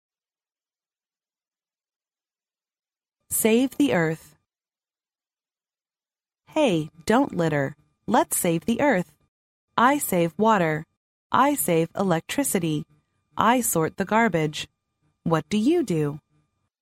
幼儿英语童谣朗读 第41期:拯救地球 听力文件下载—在线英语听力室